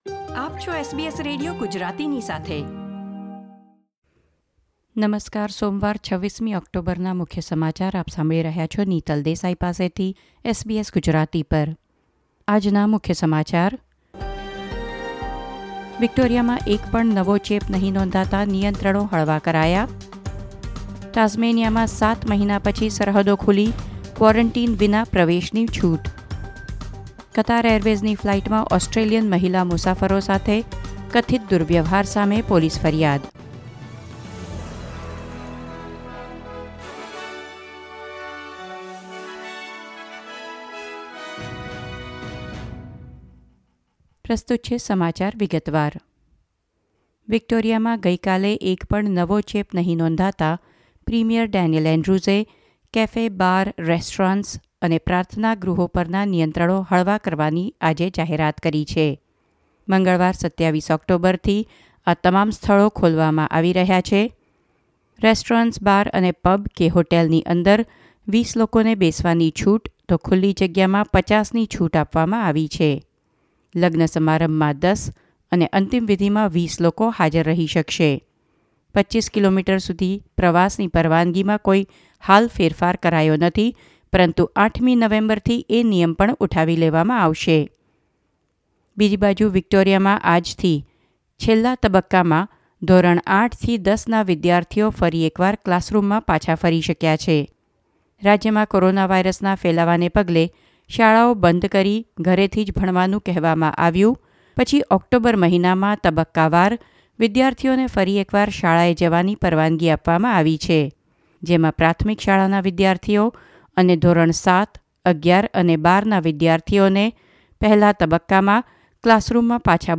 SBS Gujarati News Bulletin 26 October 2020
gujarati_2610_newsbulletin.mp3